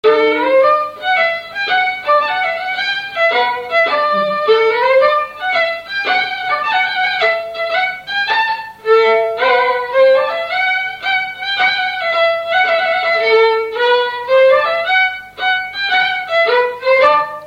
Polka
Résumé instrumental
Pièce musicale inédite